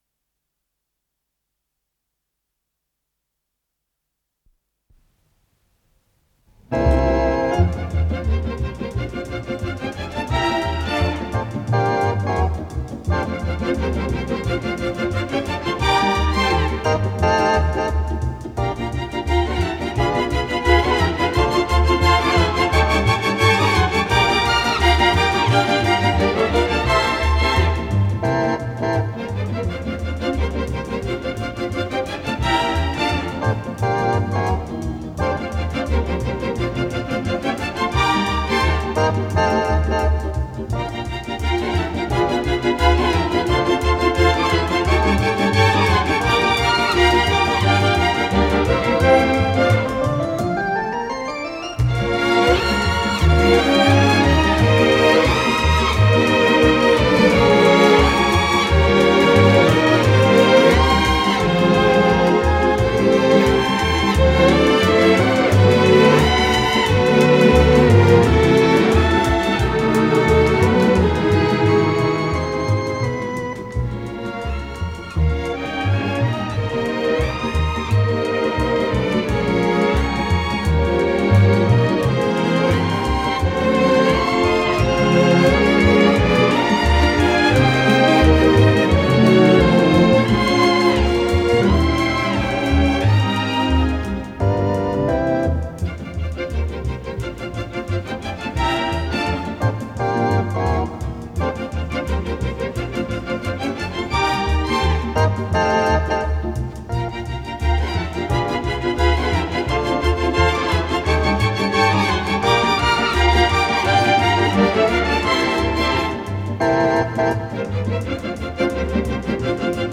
Скорость ленты38 см/с